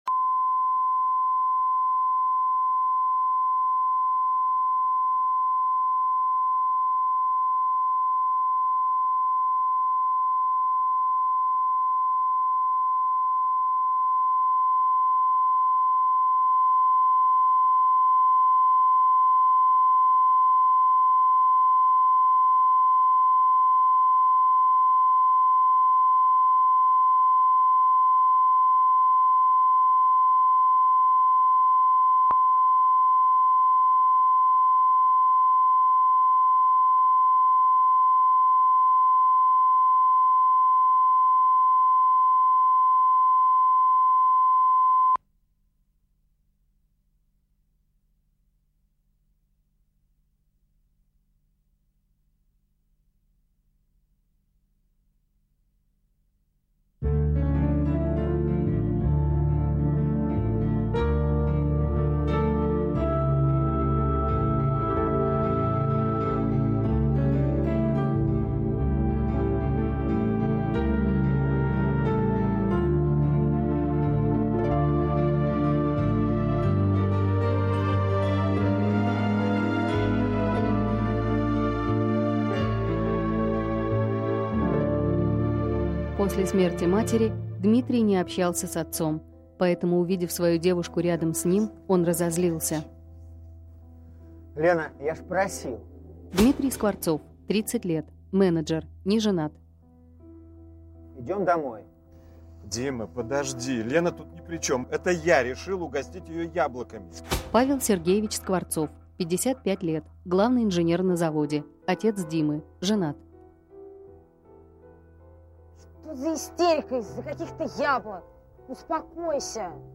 Аудиокнига Виноват